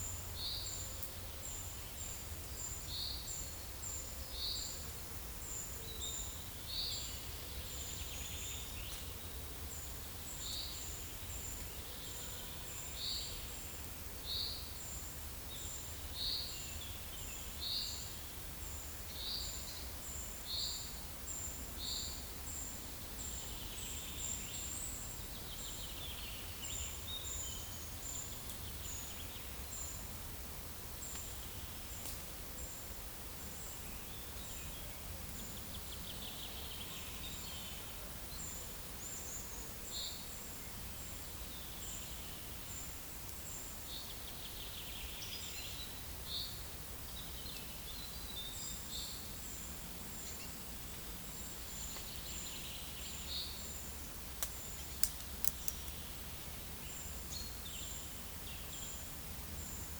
PEPR FORESTT - Monitor PAM - Renecofor
Certhia brachydactyla
Certhia familiaris
Fringilla coelebs